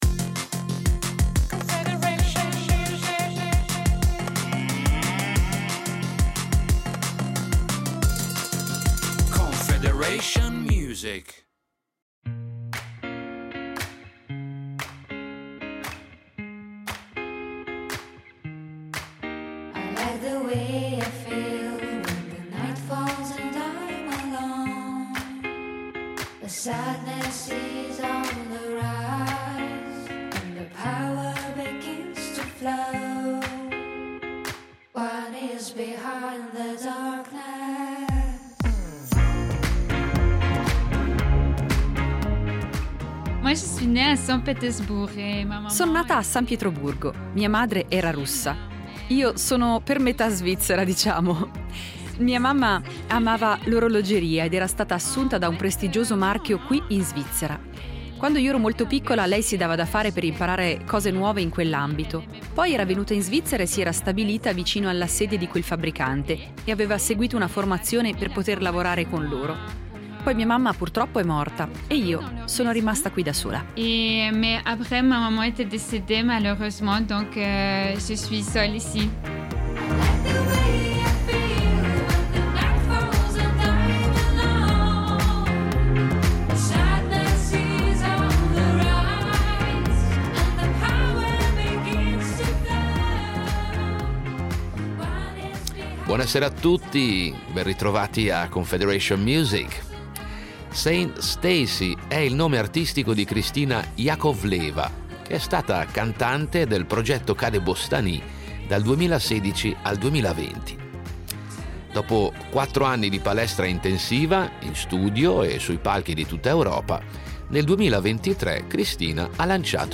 Musica pop